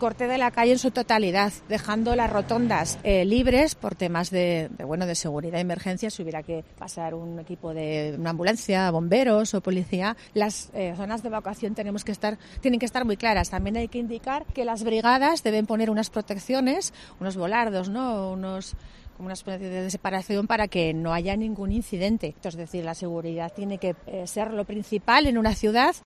La alcaldesa Lorena Orduna sobre las afecciones al tráfico por el mercadillo en Ramón y Cajal